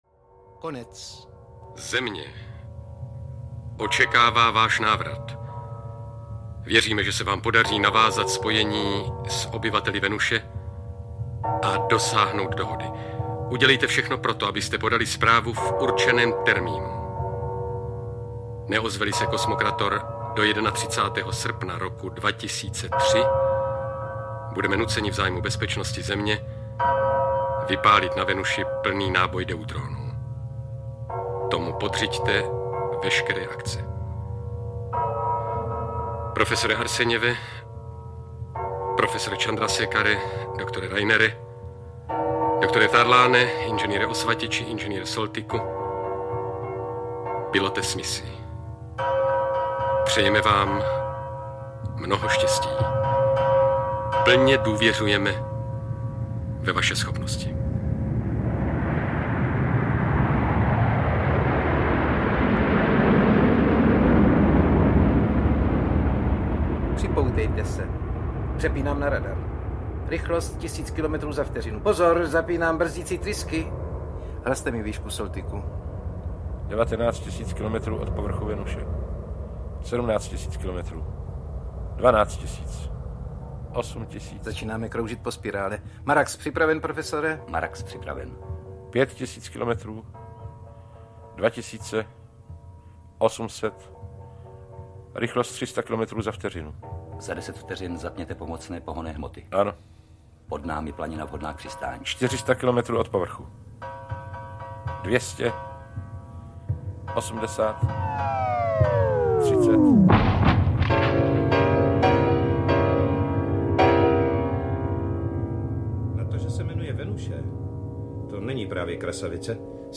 Remasterovaná nahrávka dramatizace slavného vědecko-fantastického románu.
V původní české dramatizaci z roku 1973 účinkuje plejáda známých českých herců. Ve své době byl zvukový design nahrávky vysoce novátorský, což z ní učinilo kultovní zvukový záznam pro celou generaci posluchačů.